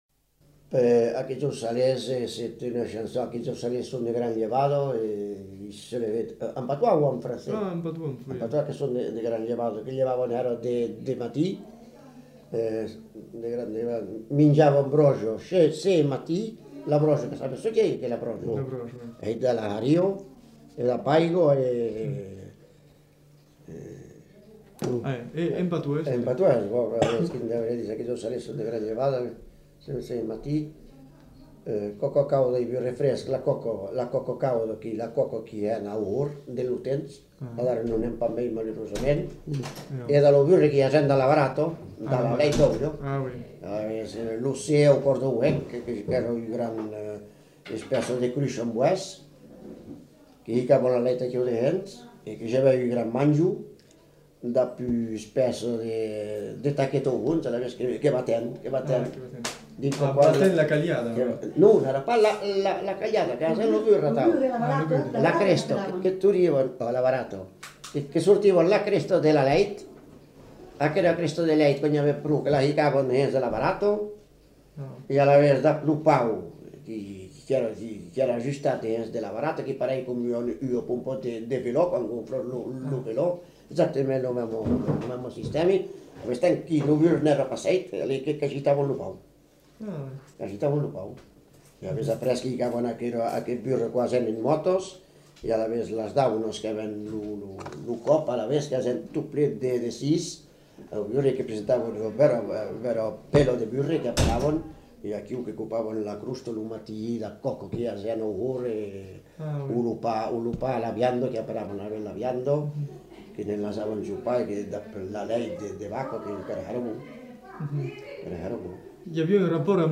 Aire culturelle : Béarn
Lieu : Bielle
Genre : témoignage thématique